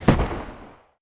EXPLOSION_Arcade_04_mono.wav